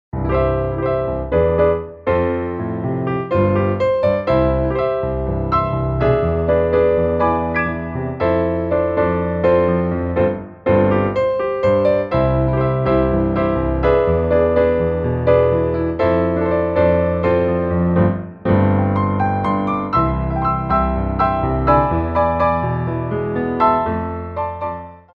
Tendus / Grand Battements
4/4 (8x8)